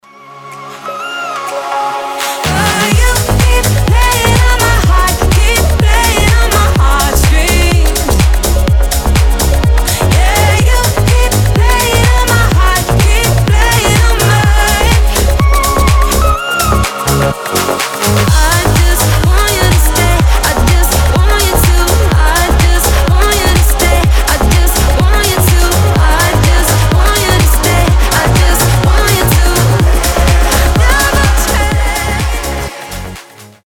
• Качество: 320, Stereo
Dance Pop
house